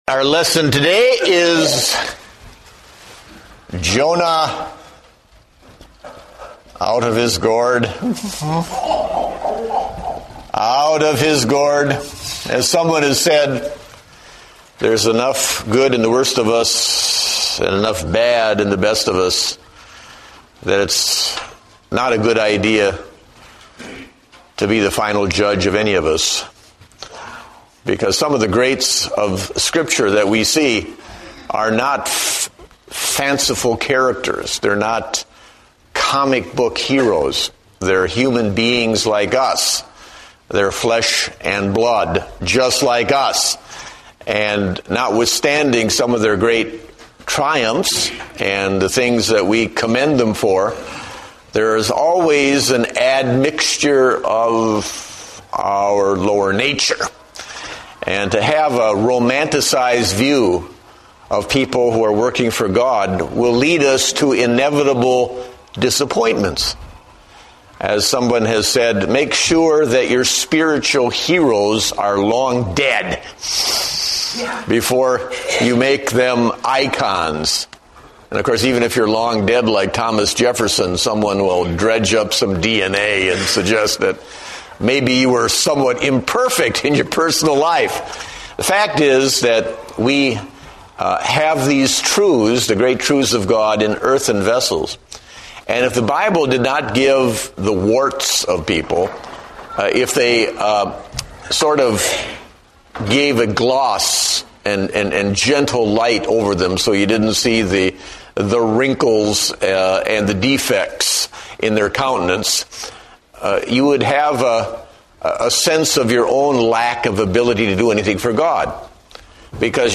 Date: October 24, 2010 (Adult Sunday School)